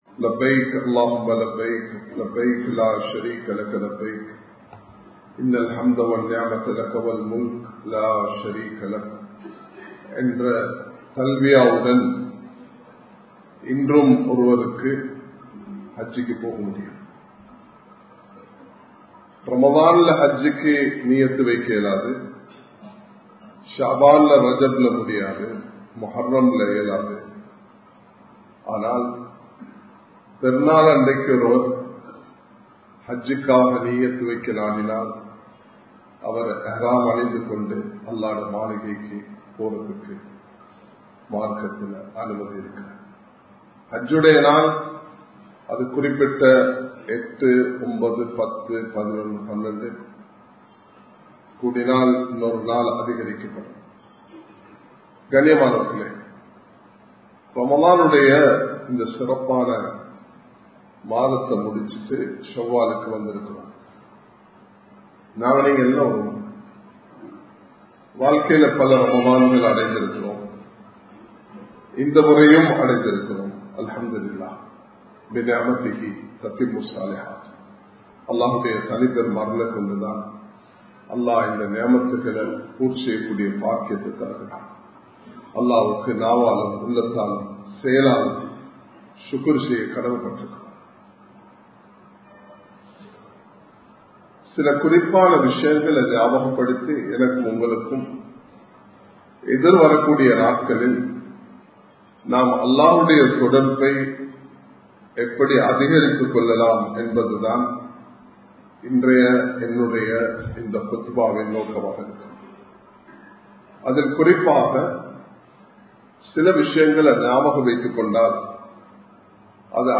ஷவ்வால் மாதத்தின் சிறப்புகள் | Audio Bayans | All Ceylon Muslim Youth Community | Addalaichenai